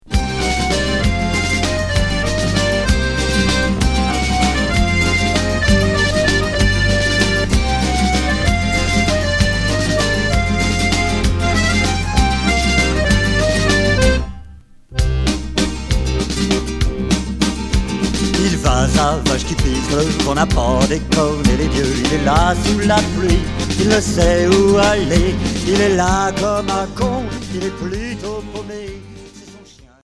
Folk Rock chanson 45t promo japonais retour à l'accueil